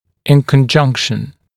[ɪn kən’ʤʌŋkʃn][ин кэн’джанкшн]вместе; в сочетании с